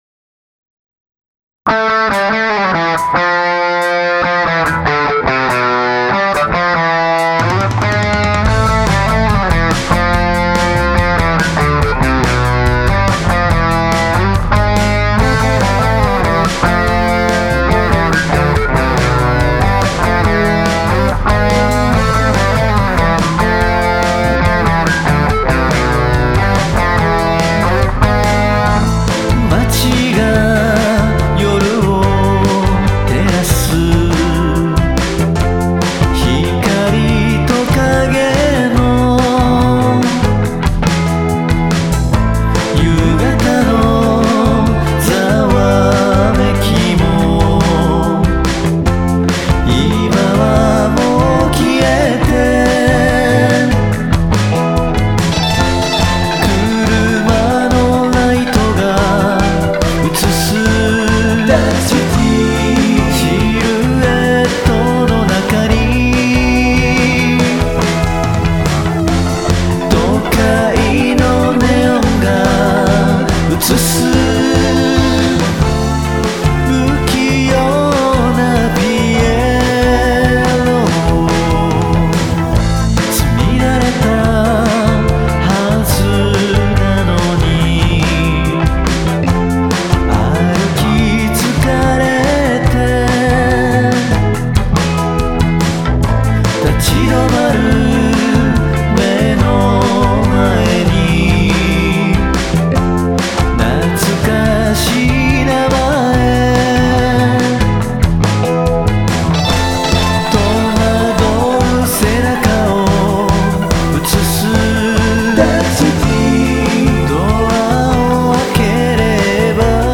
80年代ロックを意識した音作りにしてあります。